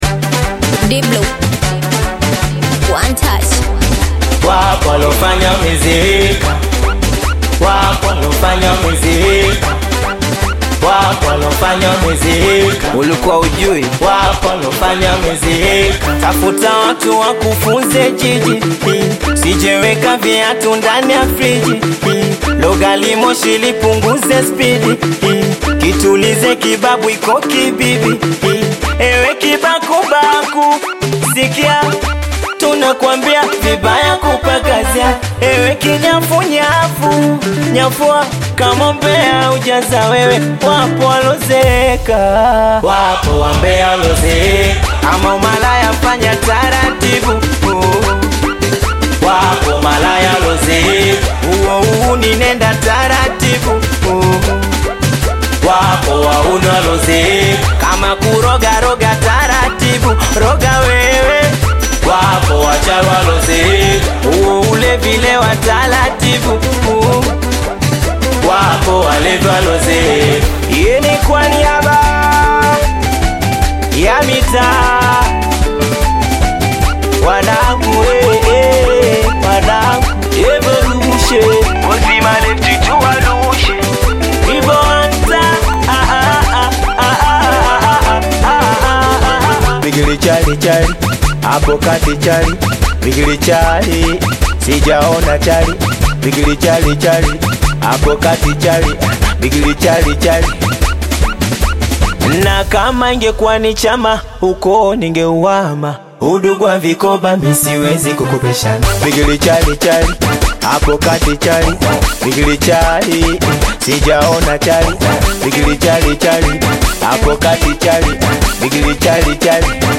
In this soulful composition
emotive vocals blend seamlessly with a melodic arrangement
rich musical layers